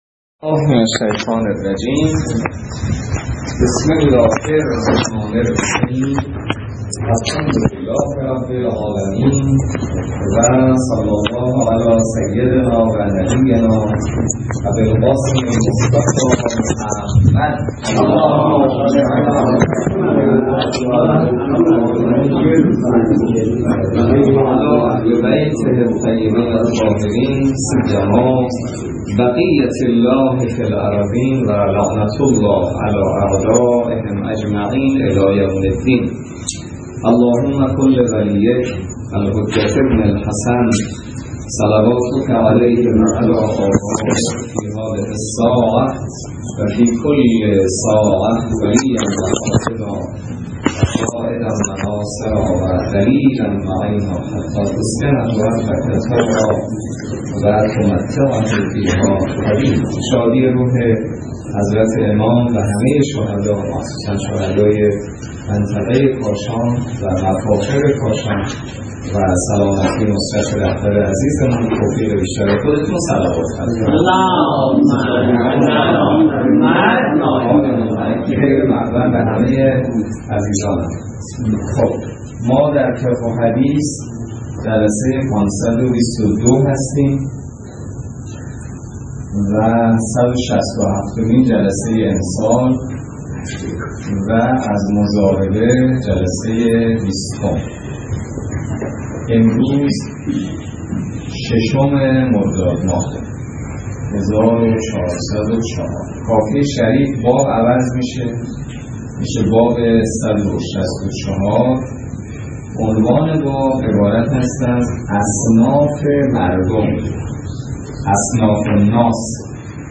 روایات ابتدای درس فقه موضوع: فقه اجاره - جلسه ۲۰